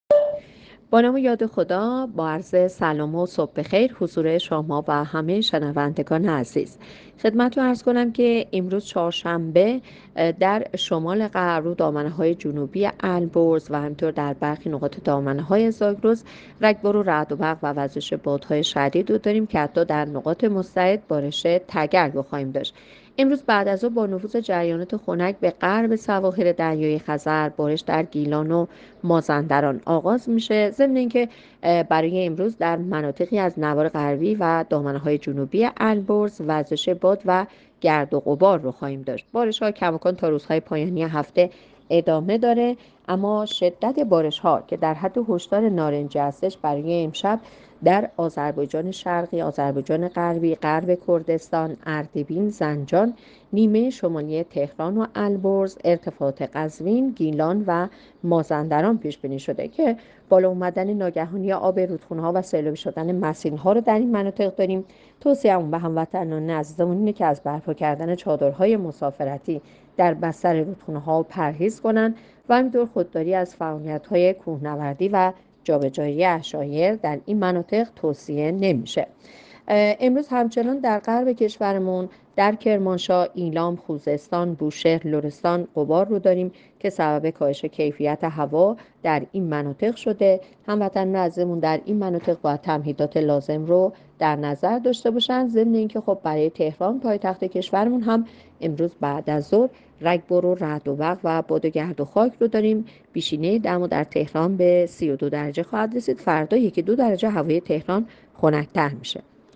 گزارش رادیو اینترنتی پایگاه‌ خبری از آخرین وضعیت آب‌وهوای ۲۴ اردیبهشت؛